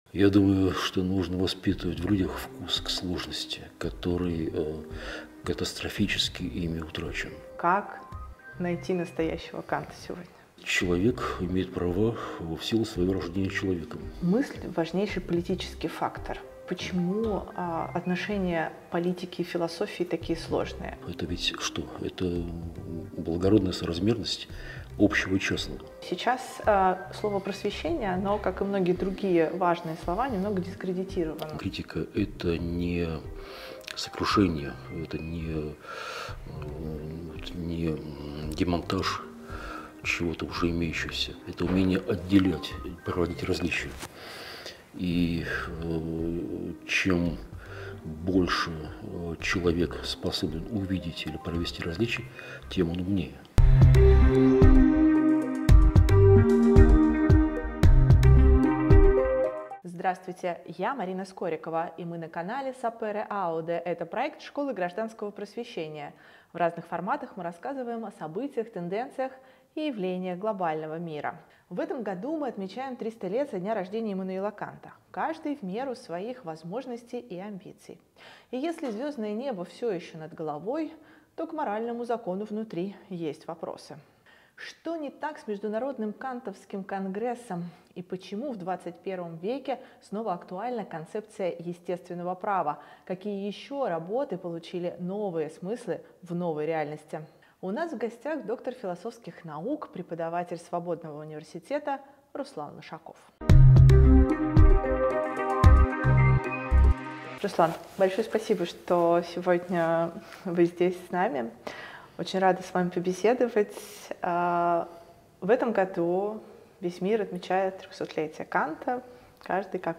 На семинаре Школы гражданского просвещения доктор философских наук